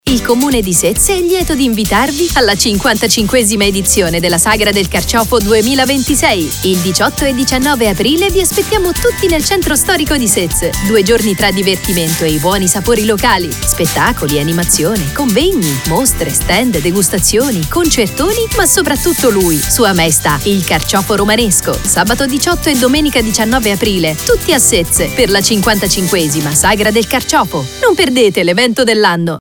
Spot pubblicitario